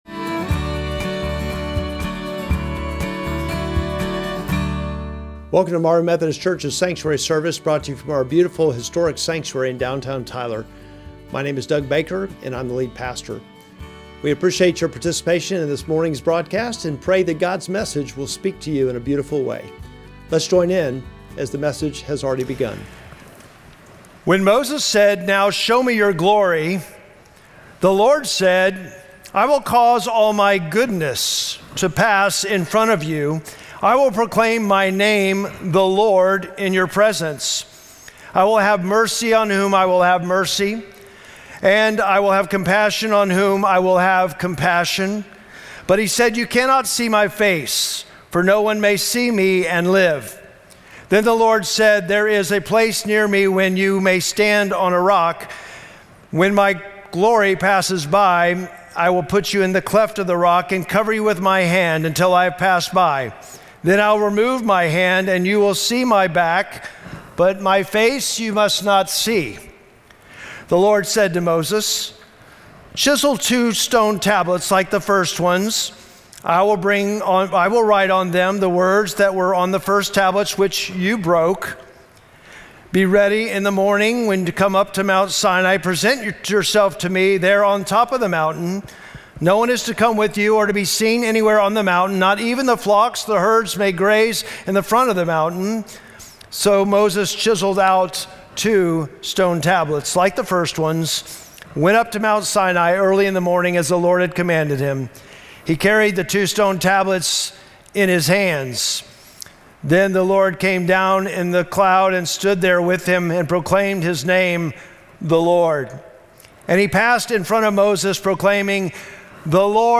Sermon text: Exodus 33:18-34:7